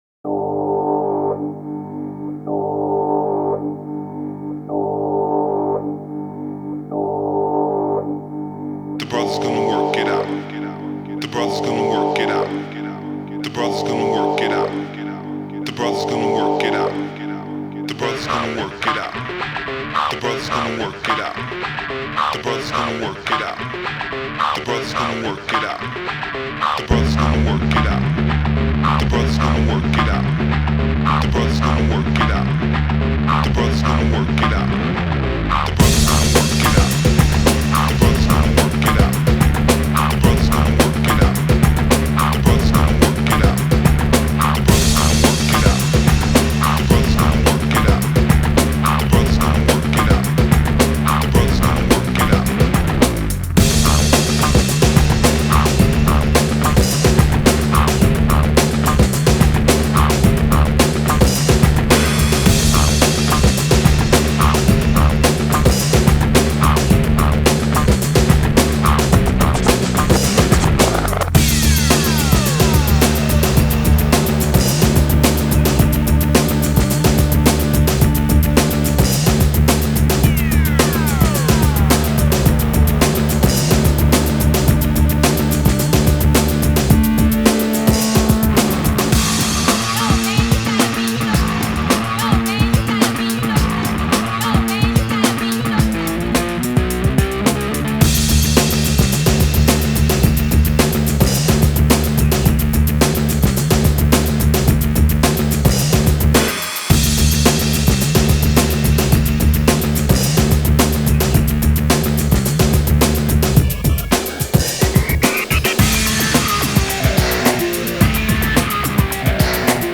Young folk these days might call this a banger.